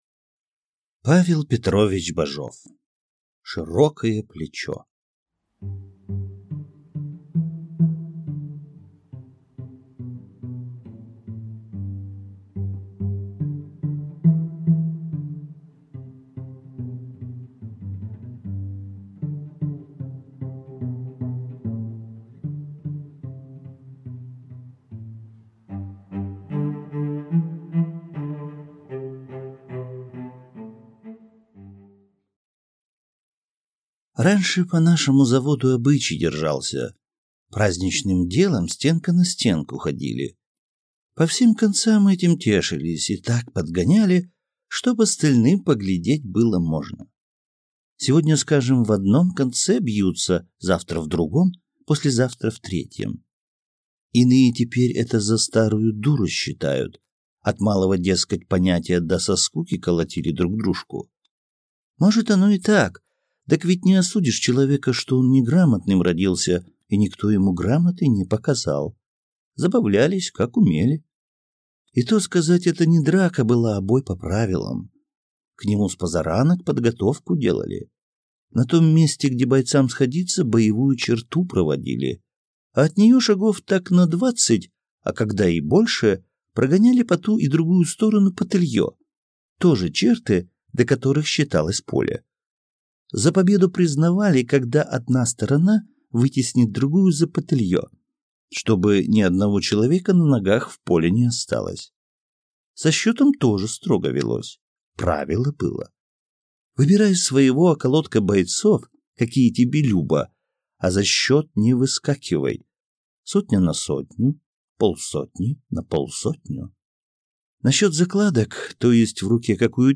Аудиокнига Широкое плечо | Библиотека аудиокниг